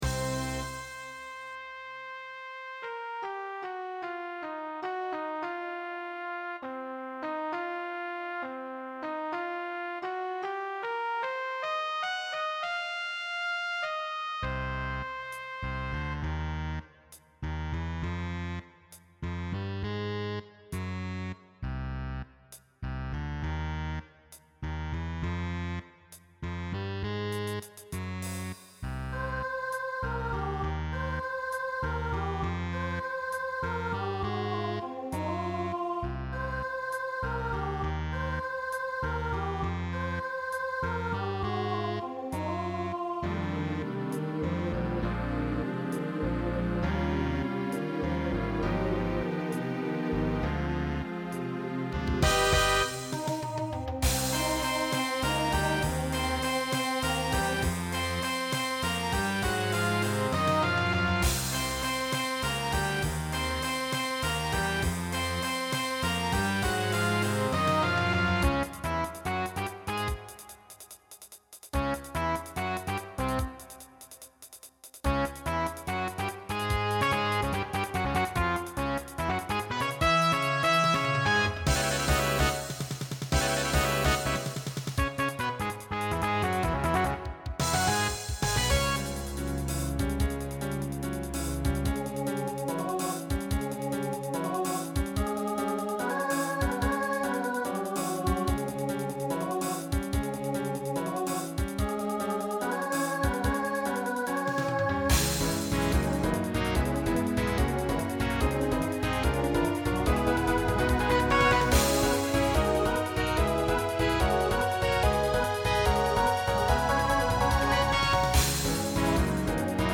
SATB Instrumental combo
Broadway/Film
Mid-tempo